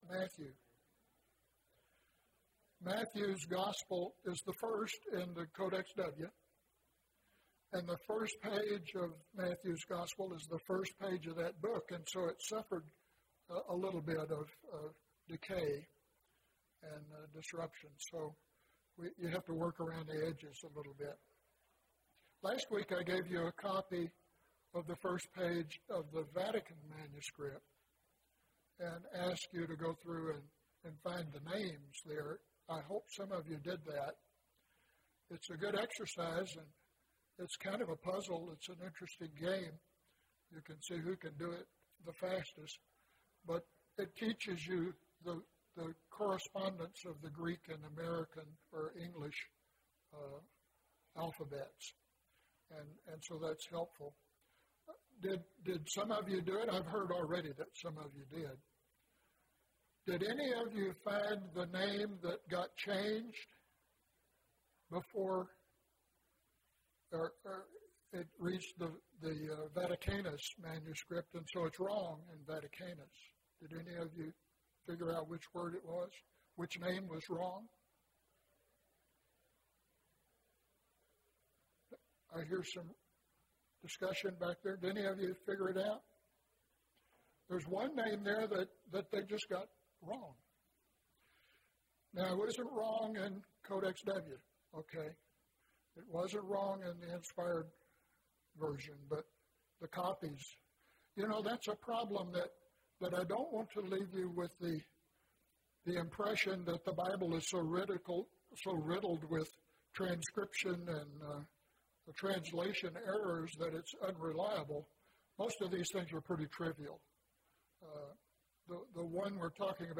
Matthew’s Genealogy of Jesus (8 of 14) – Bible Lesson Recording